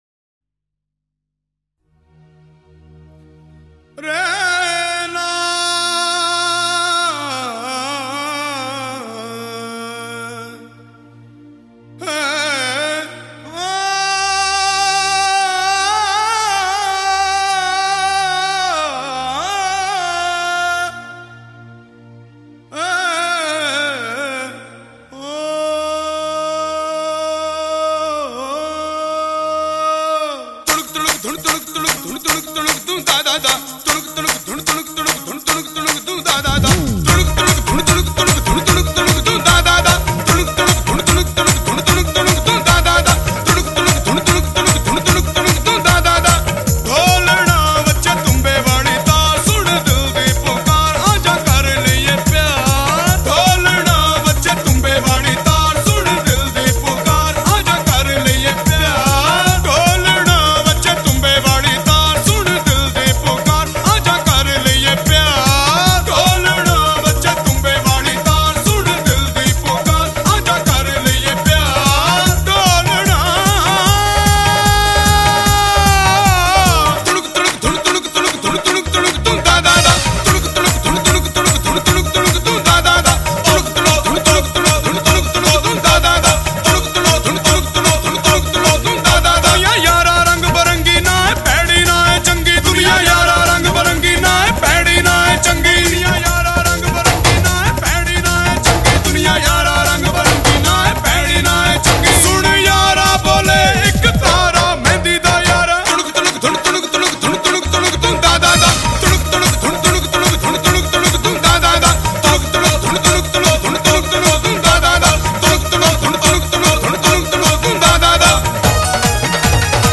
歌手是一名住在印度的Punjab(旁遮普省)的流行樂歌手.